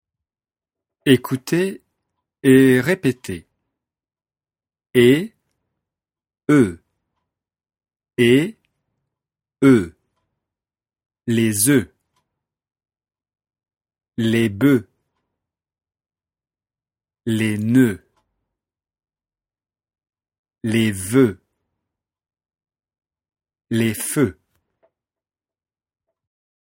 Leçon de phonétique et de prononciation, niveau débutant (A1/A2).
Écoutez et répétez : [e] / [ø]